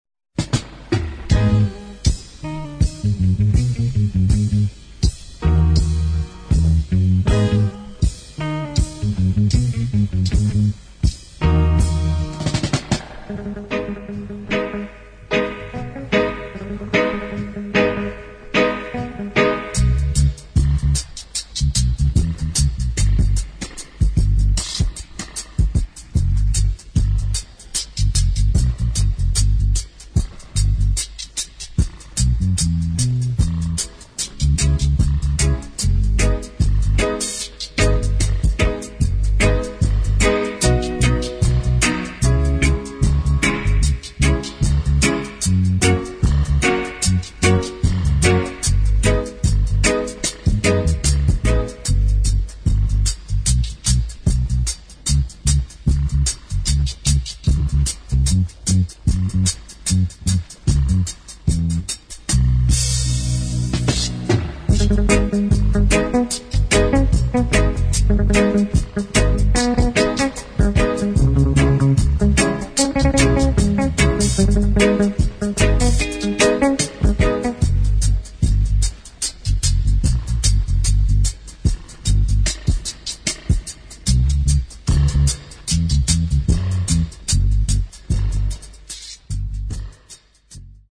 [ ROOTS REGGAE / DUB ]